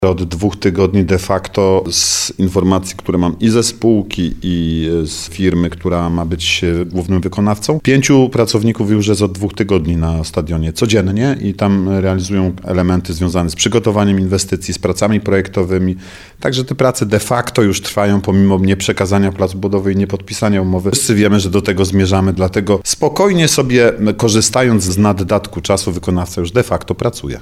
Artur Bochenek, zastępca prezydenta Nowego Sącza mówi, że wstępne ustalenia z wykonawcą mówią o podpisaniu umowy 5 lub 6 grudnia.